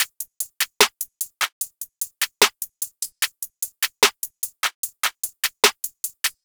MZ TL [Indie Drill - 149BPM].wav